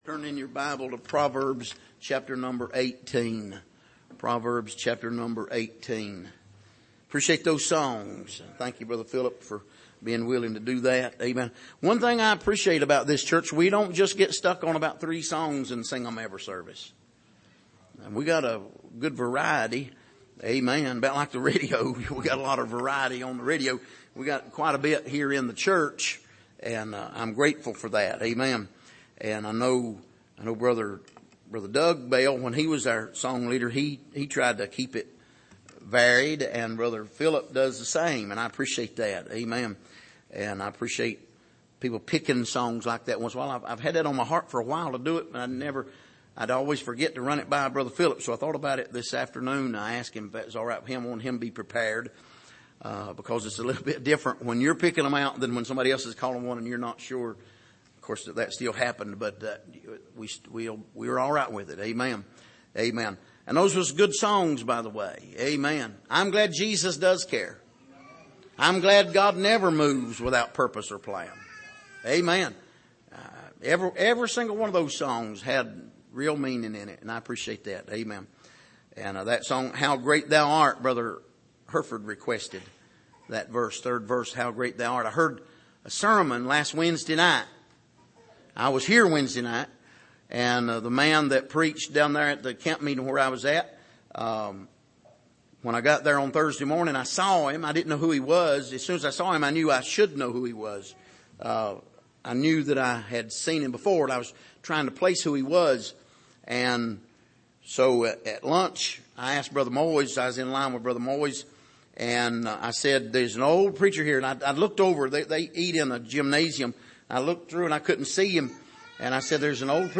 Passage: Proverbs 18:17-24 Service: Sunday Evening